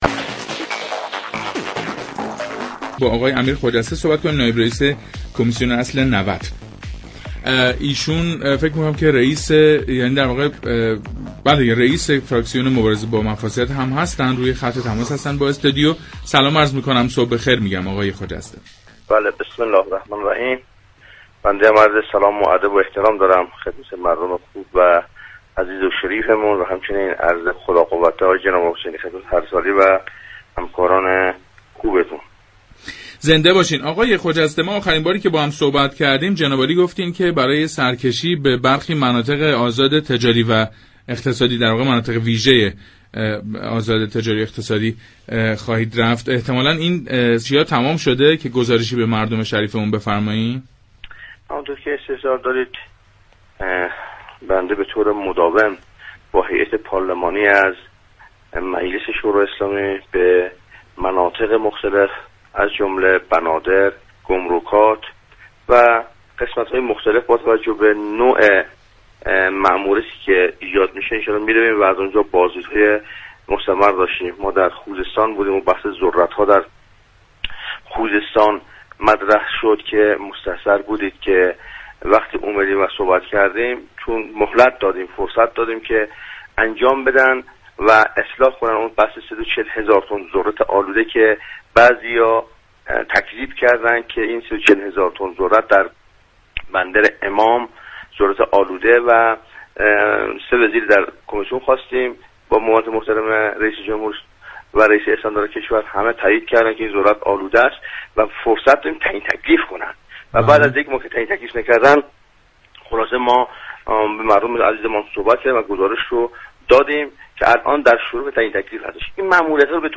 نایب رئیس كمیسیون اصل نود، در گفتگو با پارك شهر رادیو تهران درباره بازدید نمایندگان مجلس از 350 هزار تن ذرت‌ آلوده در بندر امام خمینی سخت گفت.